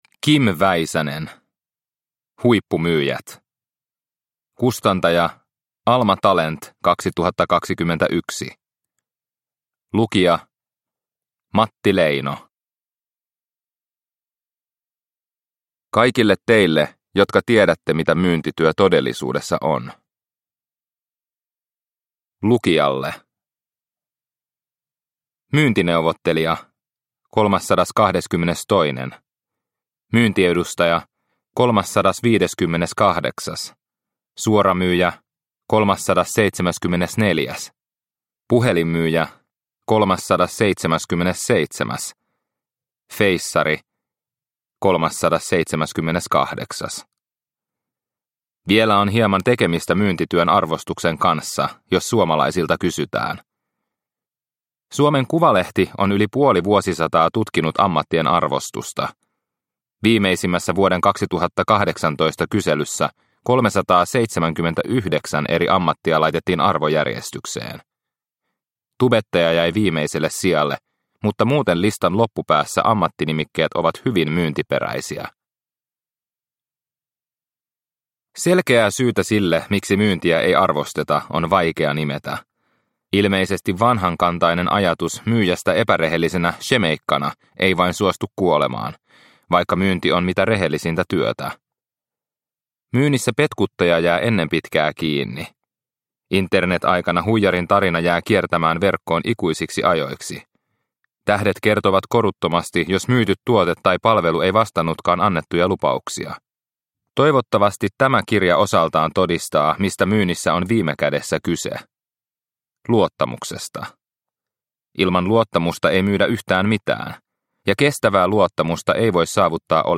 Huippumyyjät – Ljudbok – Laddas ner